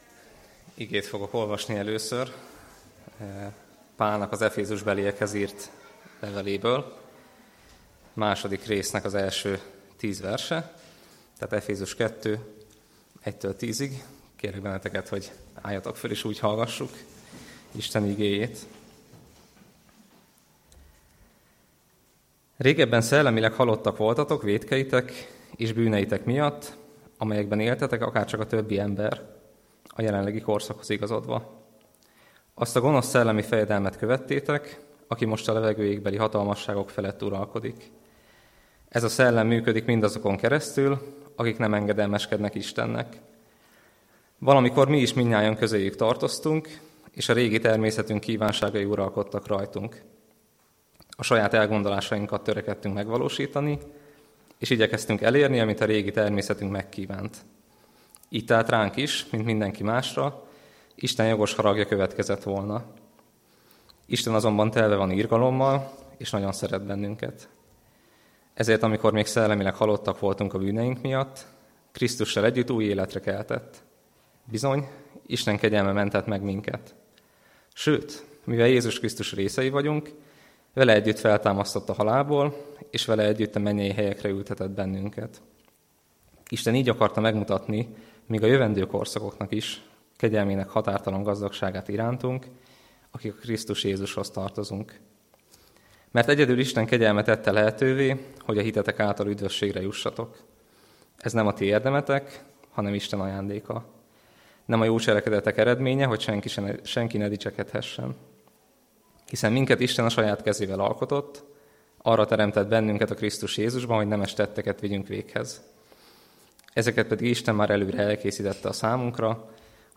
Tanítások